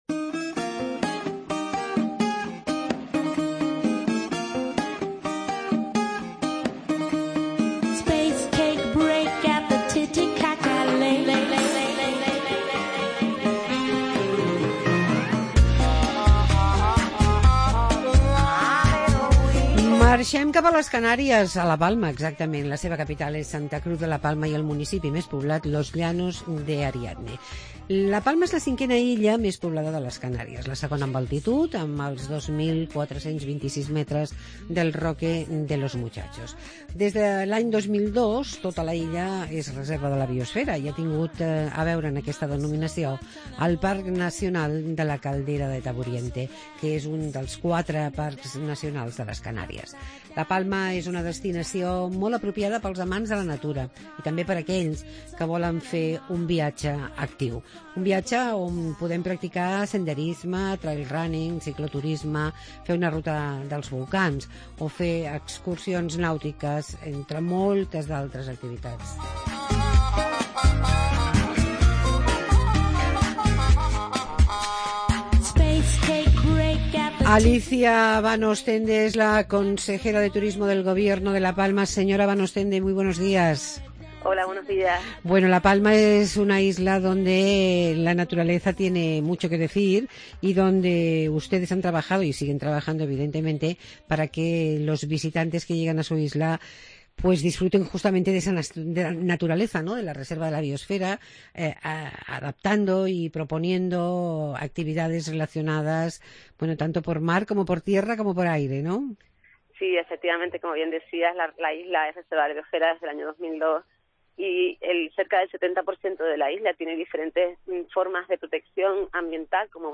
Entrevista a la Consejera de Turismo de LA PALMA, Alicia Vanoostende